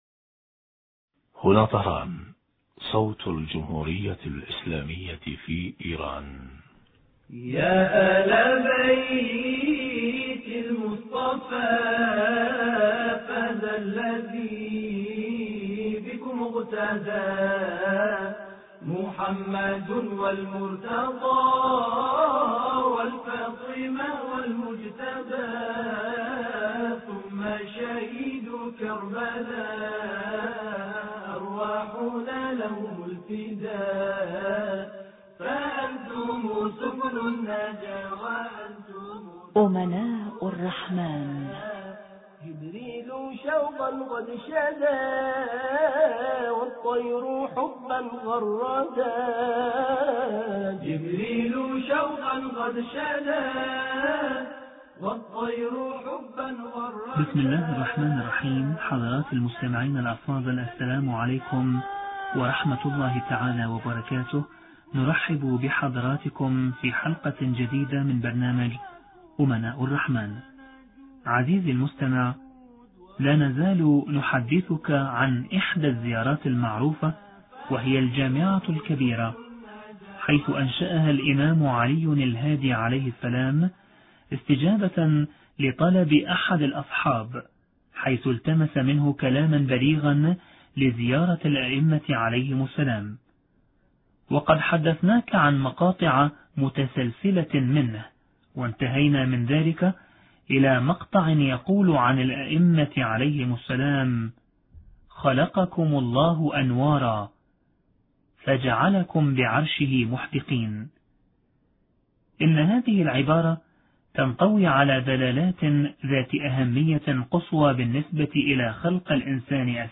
المعصومون(ع) مظاهر الانوار الالهية حوار